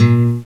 Index of /m8-backup/M8/Samples/Fairlight CMI/IIX/GUITARS
GUITAR3.WAV